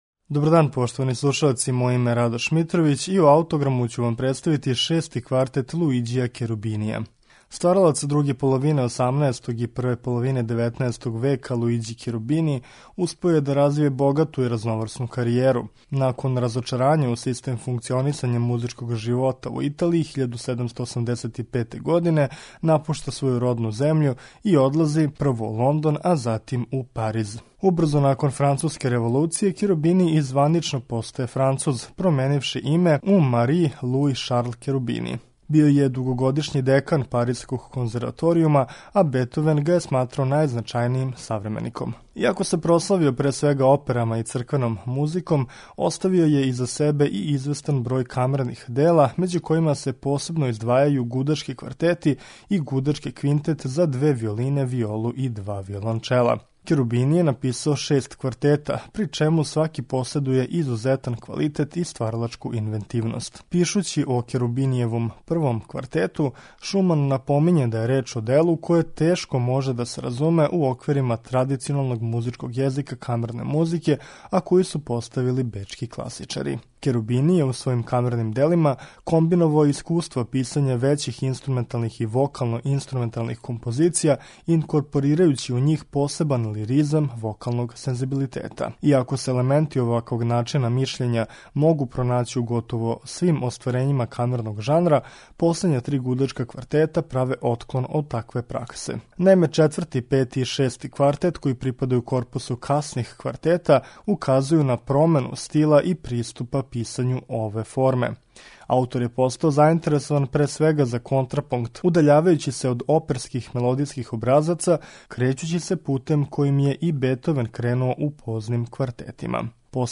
Шести гудачки квартет Луиђија Керубинија слушаћемо у извођењу квартета Мелос.